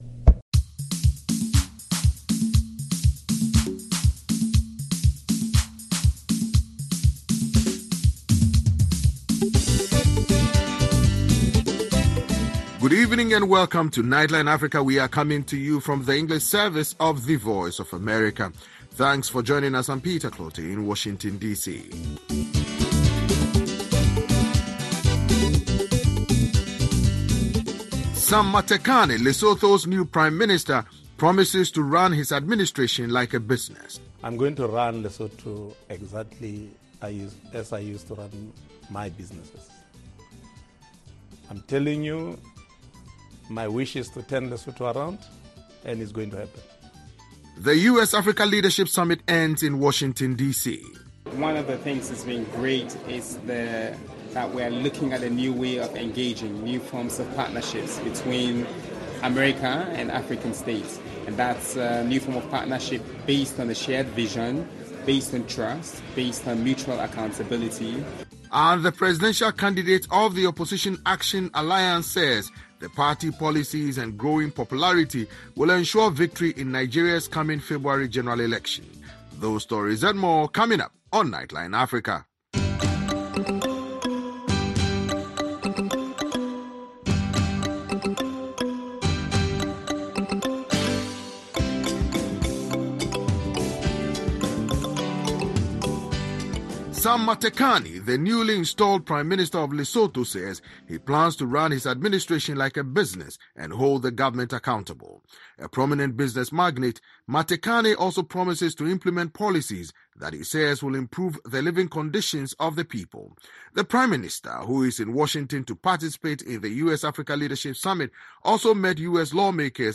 Join our host and correspondents from Washington and across Africa as they bring you in-depth interviews, news reports, analysis and features on this 60-minute news magazine show.